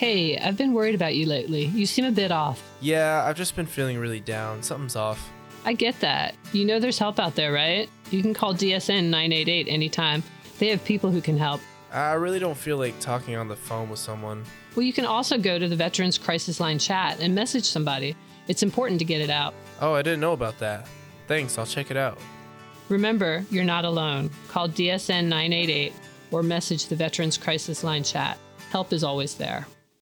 ASAP Suicide Prevention Radio Spot